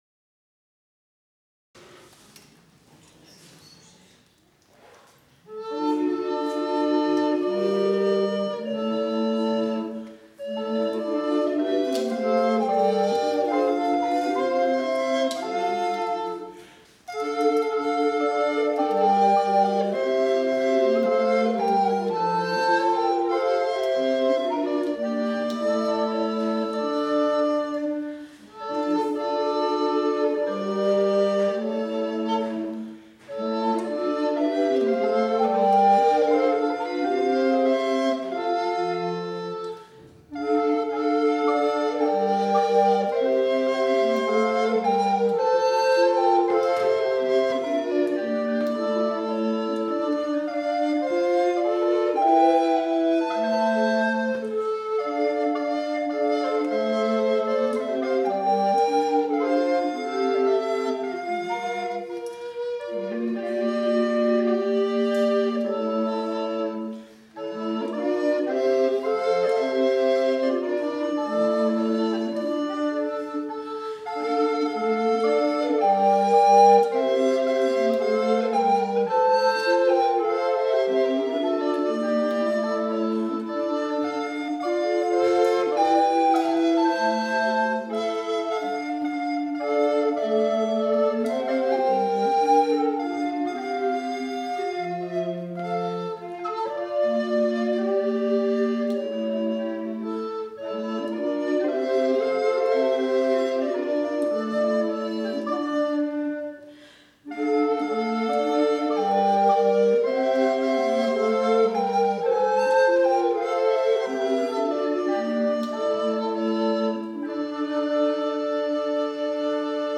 Programme des auditions
Atelier Flûtes